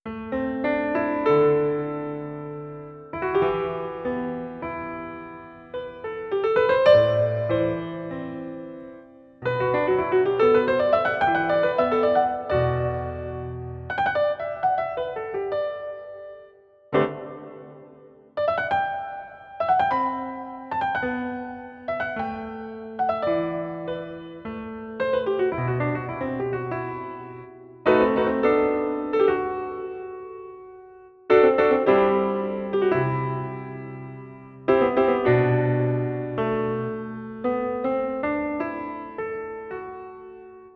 Piano contemporáneo (bucle)
instrumento musical
contemporáneo
melodía